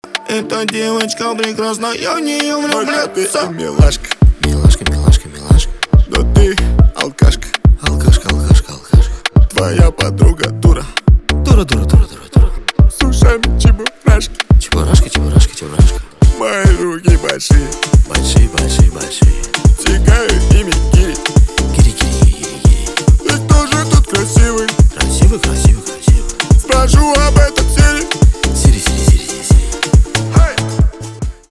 поп
битовые , гитара